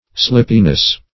Search Result for " slippiness" : The Collaborative International Dictionary of English v.0.48: Slippiness \Slip"pi*ness\, n. Slipperiness.